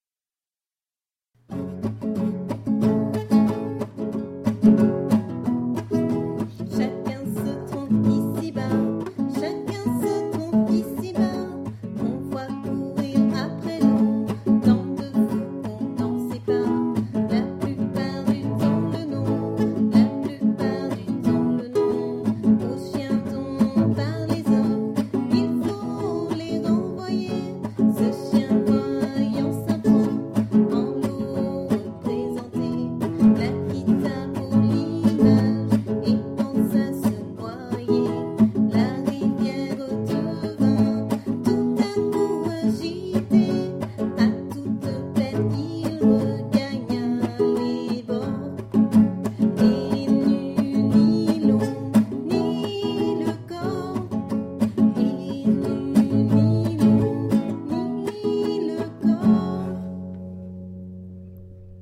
Voici trois fables dont j’ai composé la musique, à chanter pour favoriser la mémorisation des textes et les rendre plus accessibles.